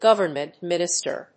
government+minister.mp3